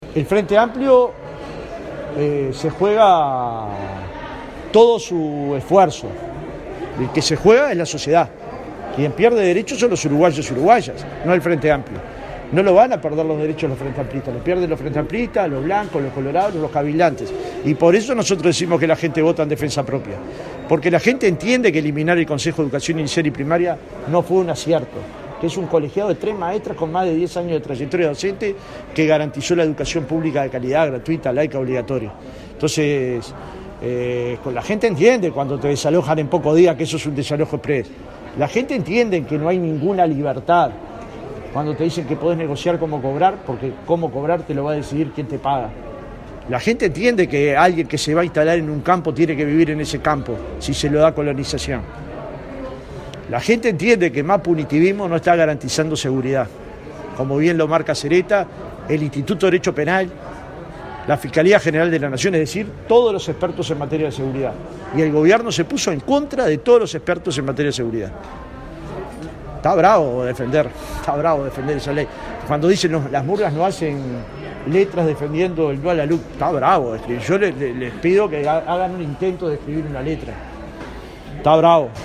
La fuerza política hizo el acto en el teatro El Galpón con presencia de allegados y legisladores del partido. En su discurso, Pereira puso énfasis en la campaña que desarrollará la fuerza política por el SI para el referéndum del 27 de marzo.